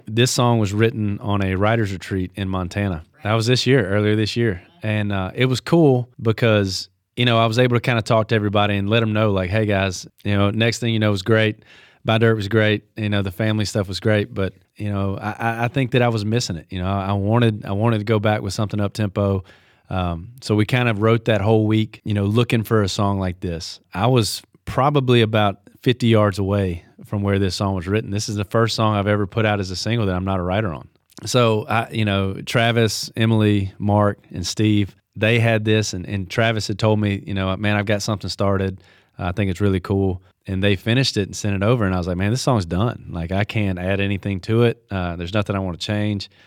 Jordan Davis talks about the inspiration and creative writing process behind his latest single “I Ain’t Sayin’.” Below, he shares what makes this track special and how it came to life in the mountains in Montana.